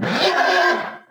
hurt2.wav